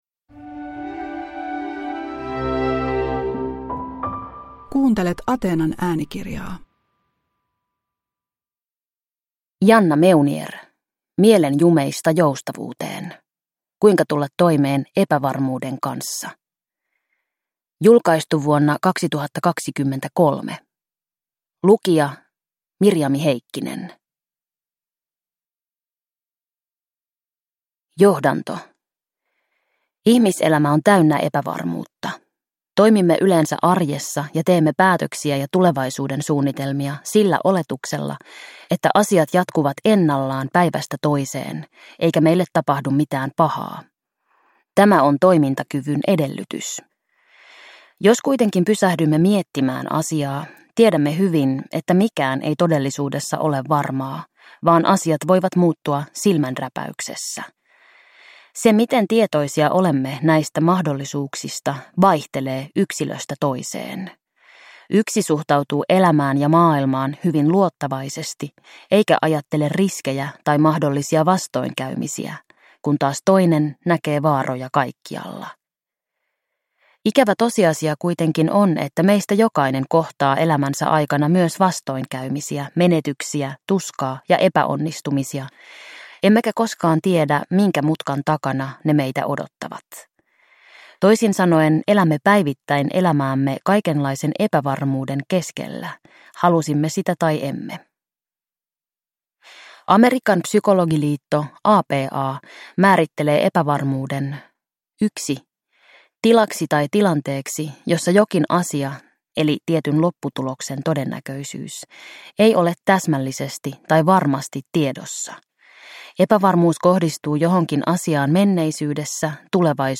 Mielen jumeista joustavuuteen (ljudbok) av Janna Meunier